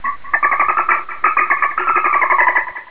El dofí
Produeixen uns sons característics.
Els dofins emeten contínuament sons similars a un xiulet que generen des d'un mecanisme situat sota l'espiracle que funciona com un sonar, és a dir, és un sistema per eco col·locar els objectes (semblant al que tenen els rat-penats) gràcies al qual poden navegar i detectar les preses amb facilitat.
delfin2.wav